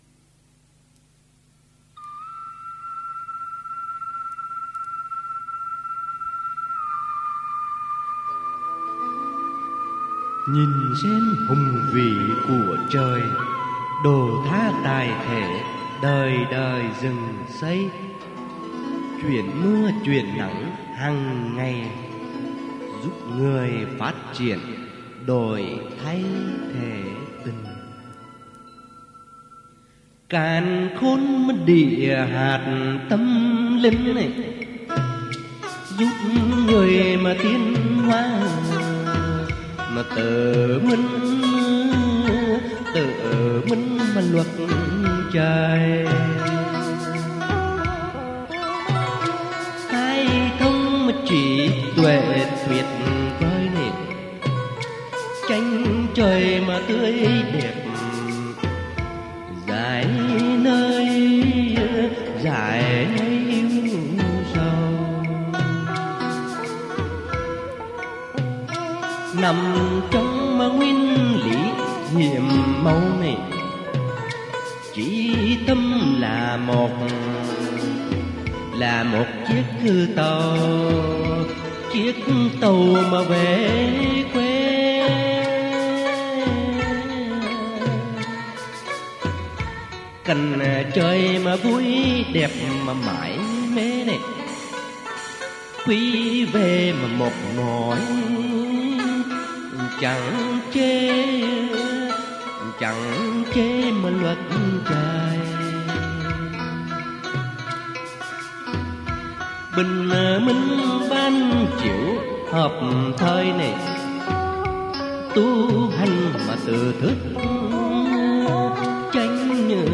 Dân Ca & Cải Lương
theo điệu bài chòi miền Trung